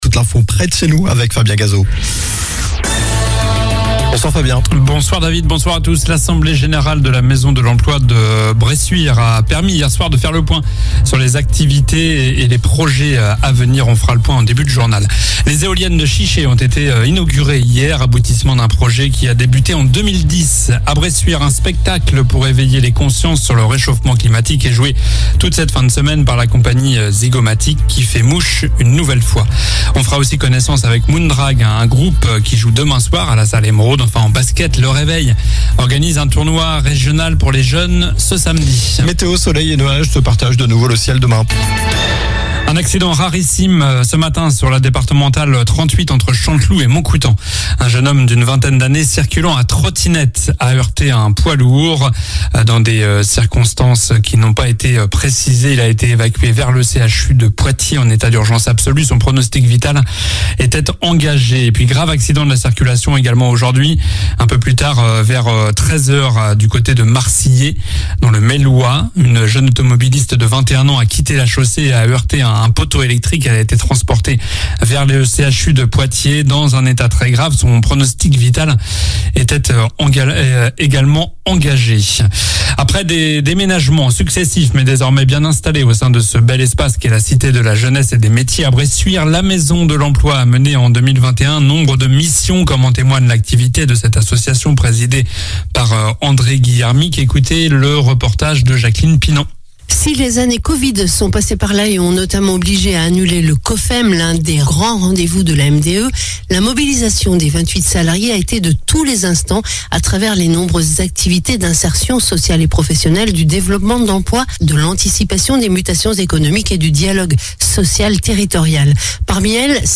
Journal du jeudi 19 mai (soir)